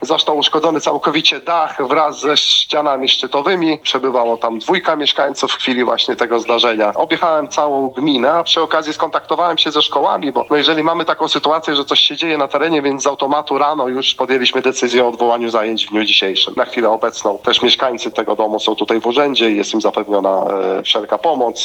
[AKTUALIZACJA] – Na szczęście nikomu nic się nie stało – mówi wójt gminy Bernard Błaszczuk.